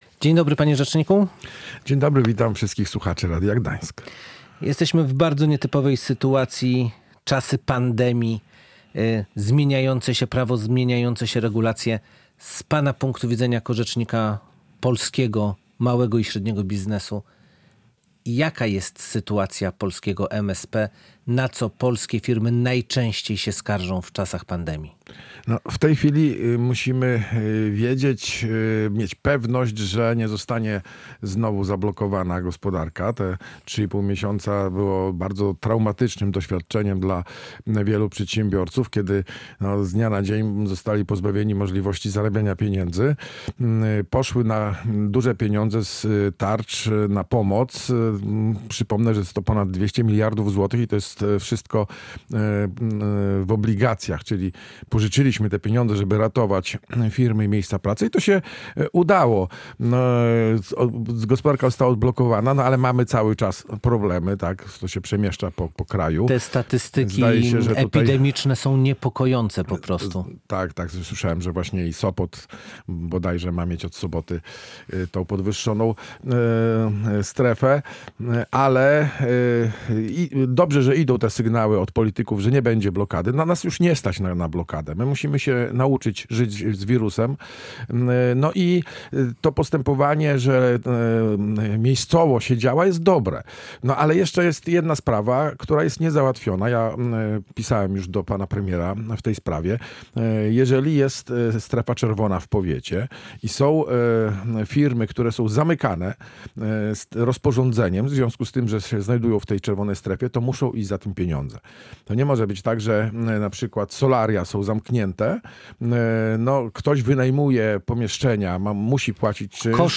rozmawiał Adam Abramowicz, Rzecznik Małych i Średnich Przedsiębiorców.